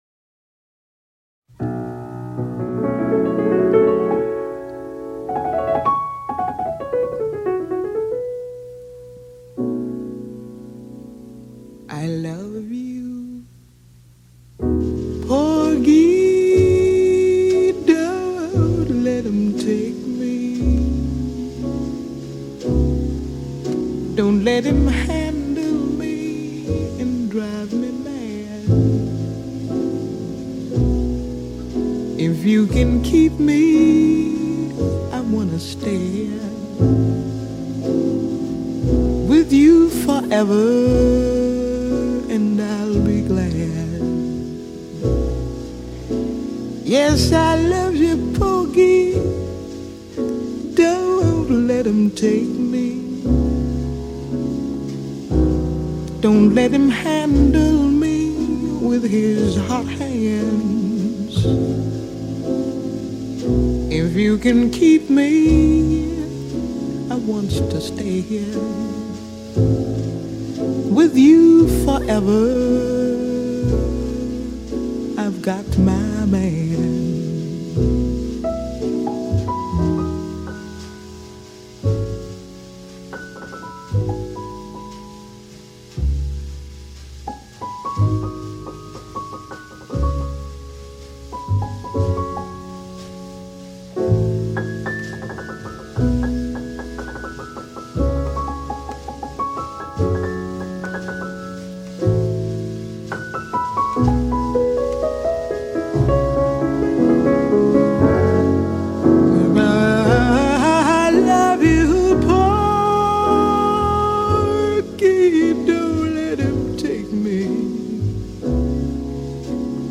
Jazz, Blues, Vocal